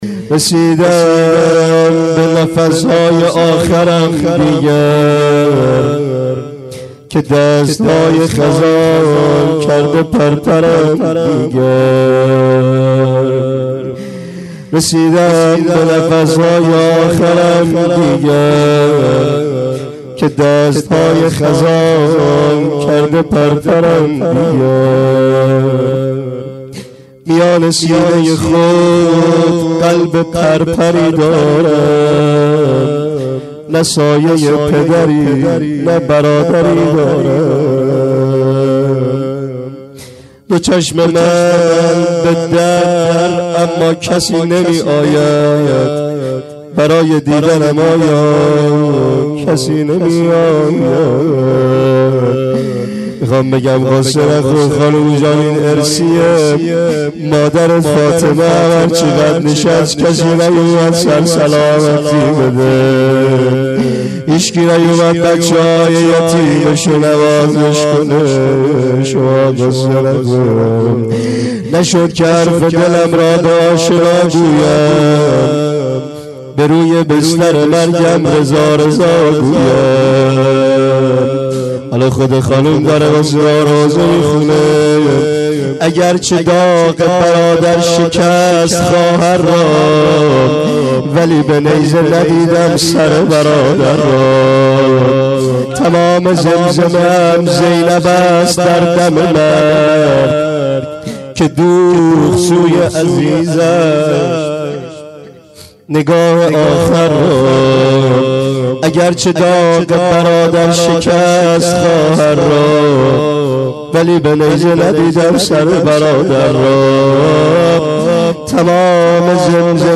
روضه حضرت معصومه س. رسیده ام به نفسهای آخرم دیگر.MP3